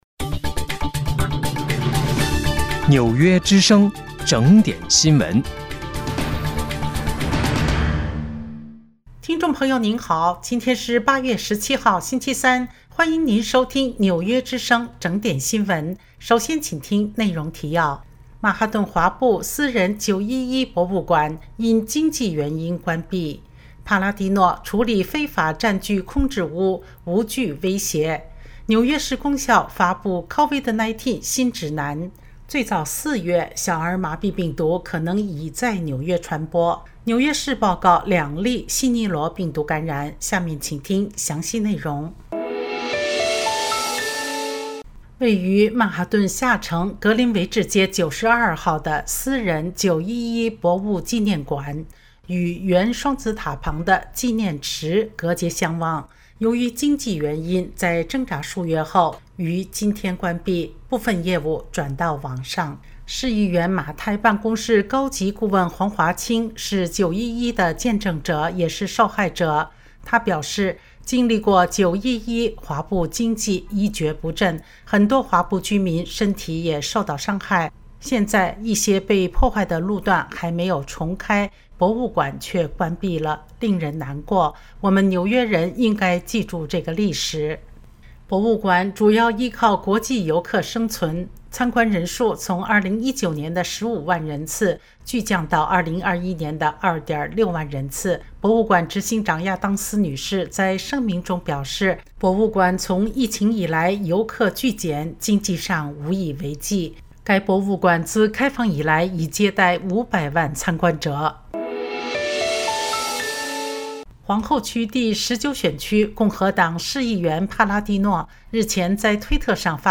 8月17日（星期三）纽约整点新闻